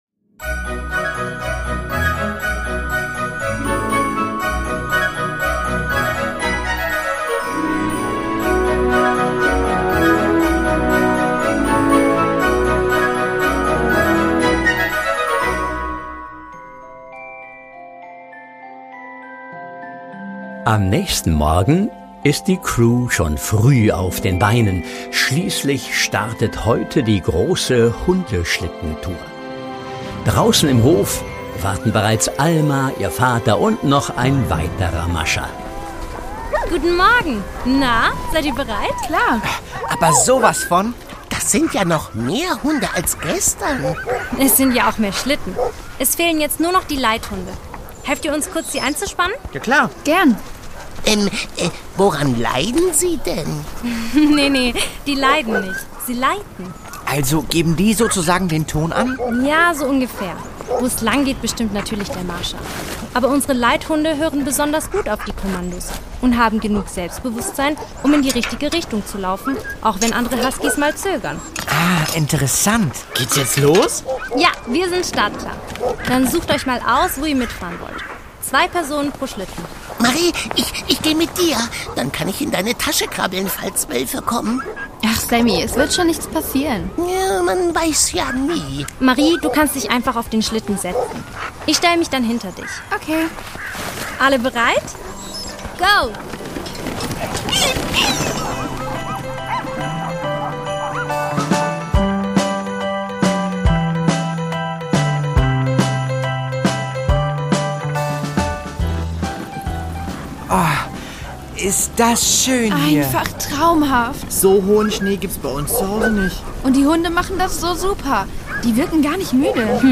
Lappland: Ein Rätsel im Schnee (4/24) | Die Doppeldecker Crew | Hörspiel für Kinder (Hörbuch)